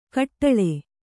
♪ kaṭṭaḷe